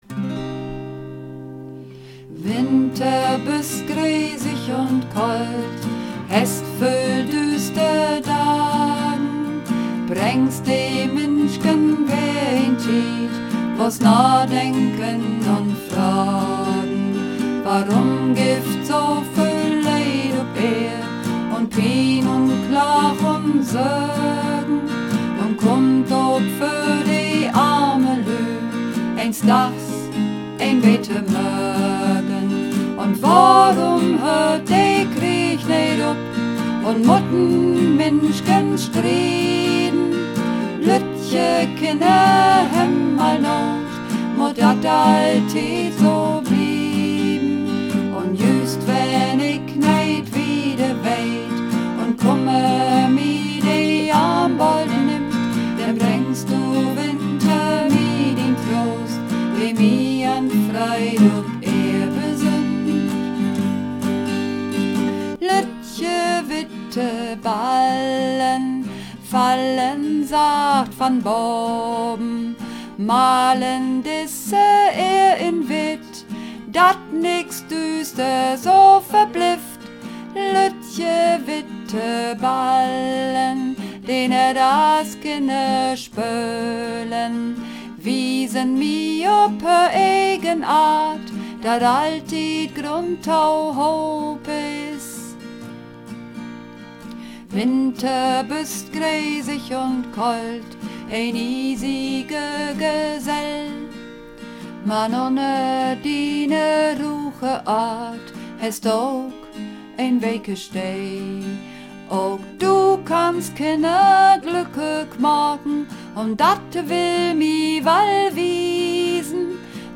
Übungsaufnahmen - Lüttje witte Ballen
Lüttje witte Ballen (Alt und Sopran)
Luettje_witte_Ballen__1_Alt_und_Sopran.mp3